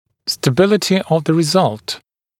[stə’bɪlətɪ əv ðə rɪ’zʌlt][стэ’билэти ов зэ ри’залт]стабильность результата